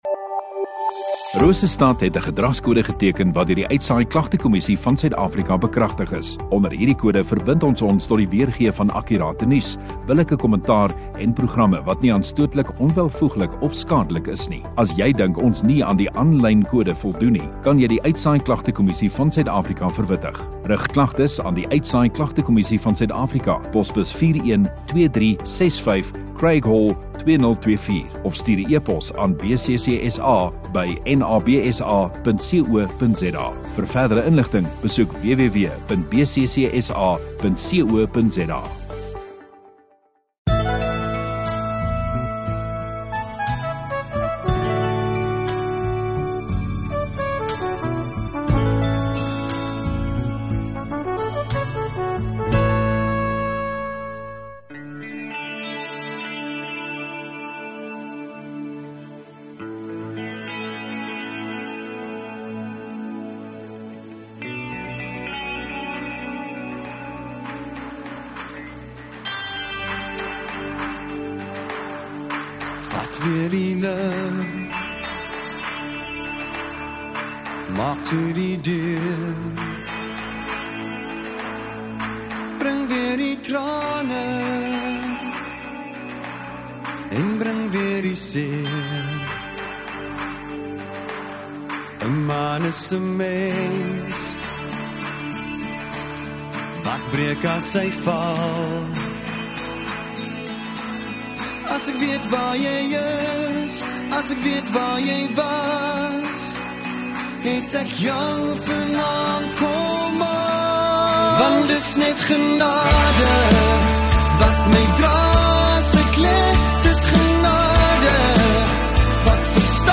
22 Oct Sondagaand Erediens